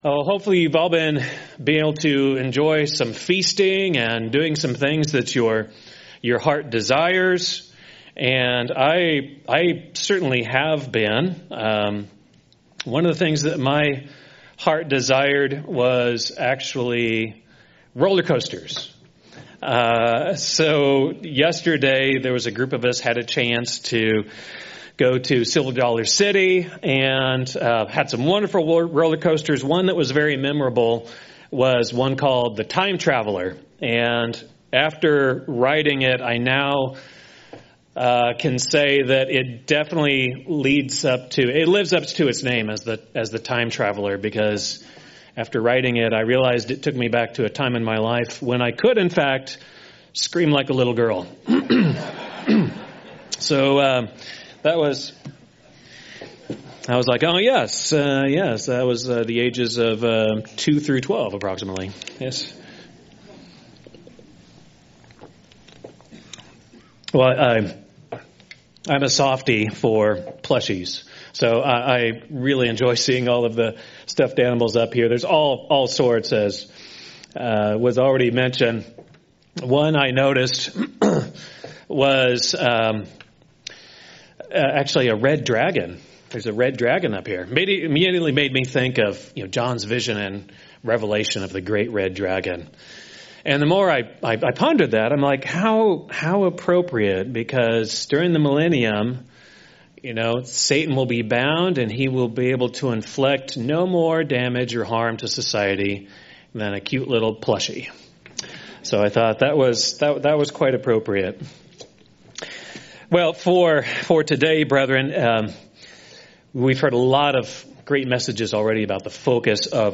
This sermon is about the topic of God's kingdom rule and authority over the people of God and how believers are thus strangers and foreigners amongst the nations today. The sermon examines the biblical language of exile and resident alien used to describe the early Christians, focusing on how we are to live as "exiles in Babylon" while we walk by faith, looking for the ultimate rule of God's kingdom over the entire earth during the Millennium.